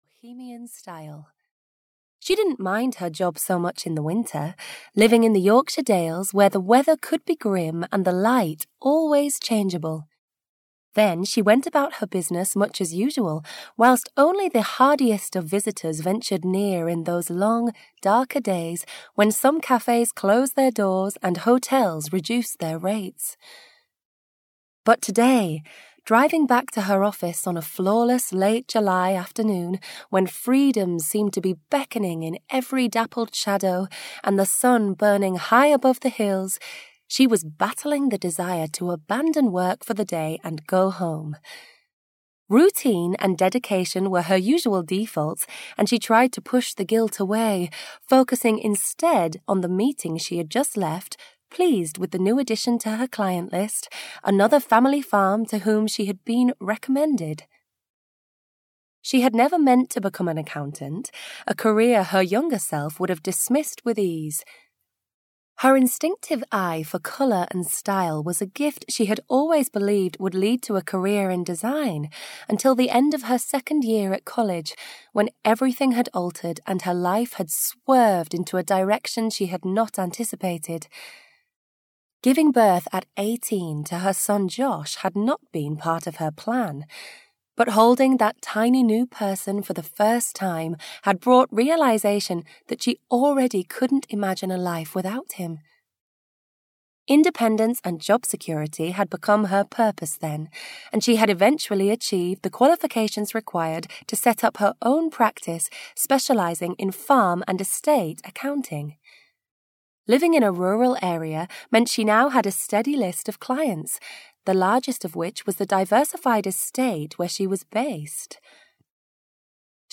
A Summer of Second Chances (EN) audiokniha
Ukázka z knihy